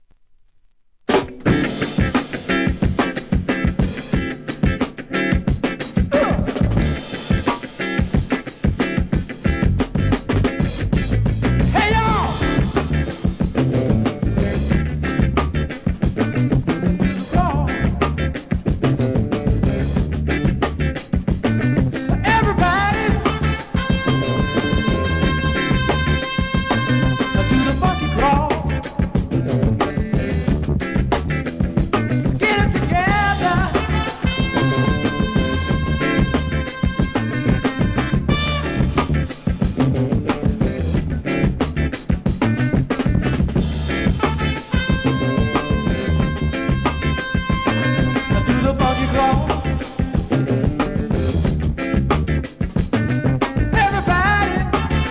1. SOUL/FUNK/etc...
ミシガン高校生バンドのDEEP FUNK!!